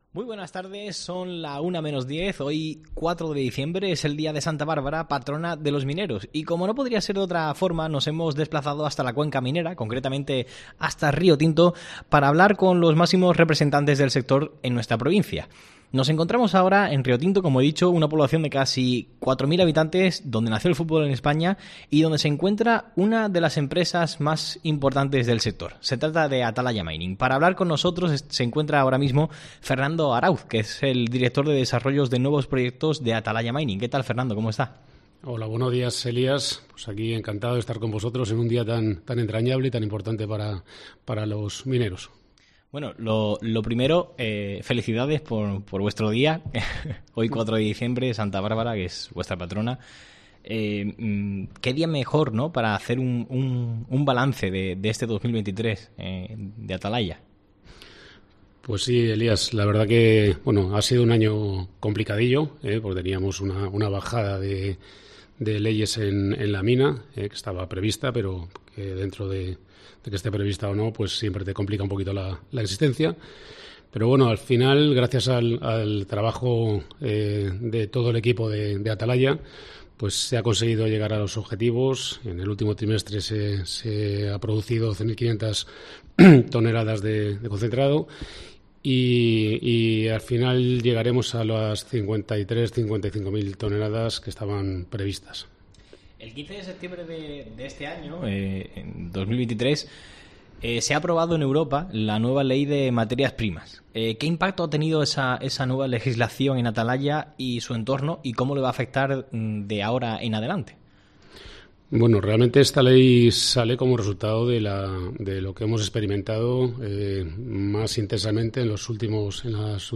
Entrevista en Atalaya Mining